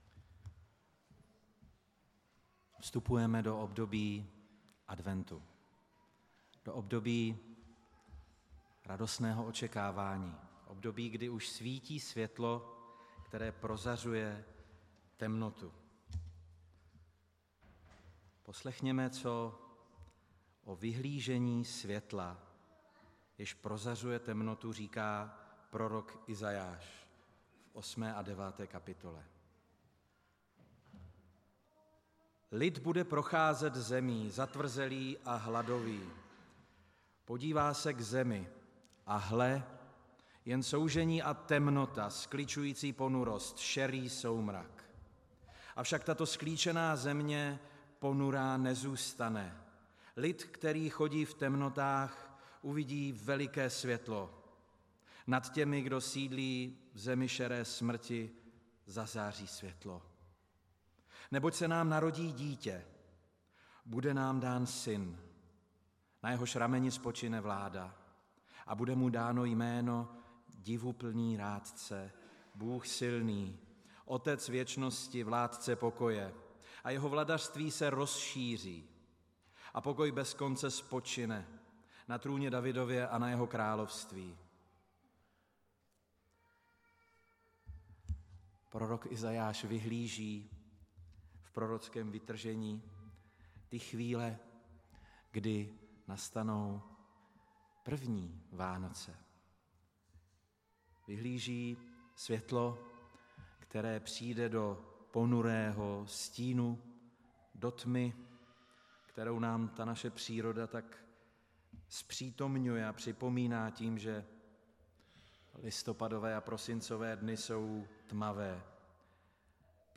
Událost: Kázání